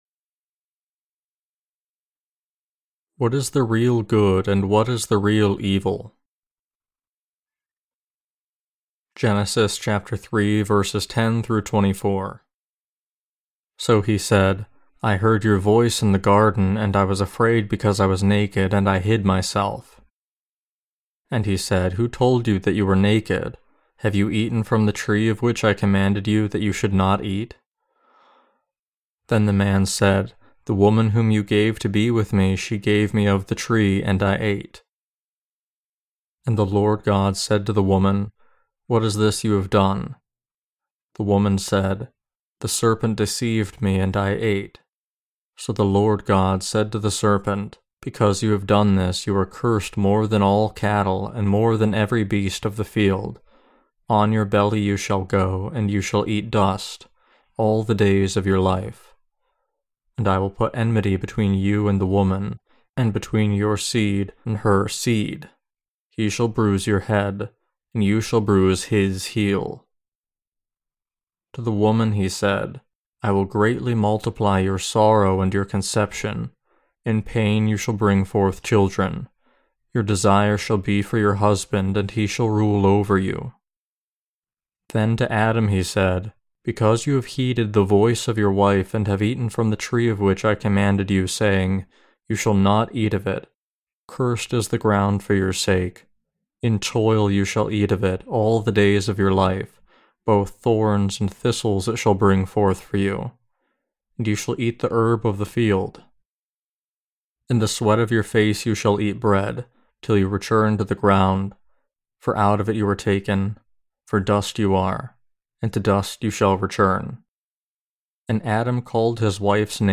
Sermons on Genesis (II) - The Fall of Man and The Perfect Salvation of God Ch3-10.